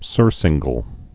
(sûrsĭnggəl)